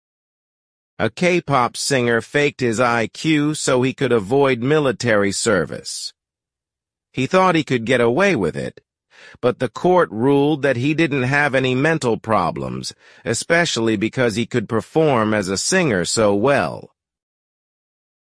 Super Realistic AI Voices For Your Listening Practice